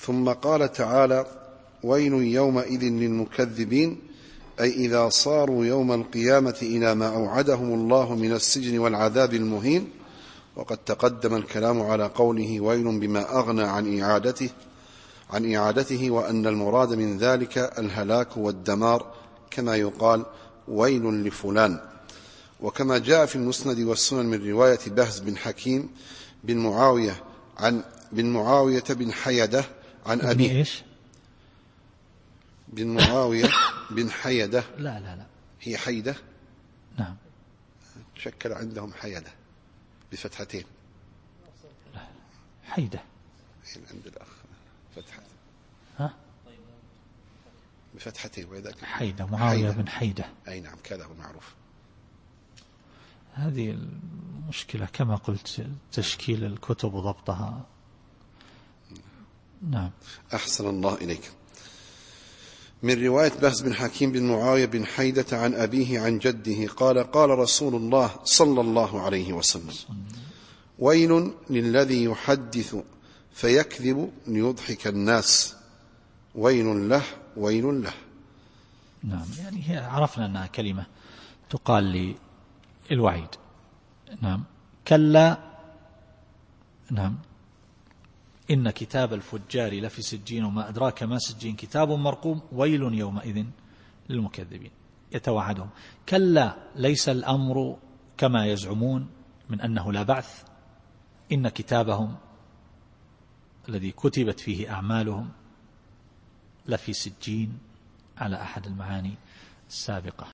التفسير الصوتي [المطففين / 10]